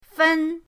fen1.mp3